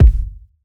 Medicated Kick 8.wav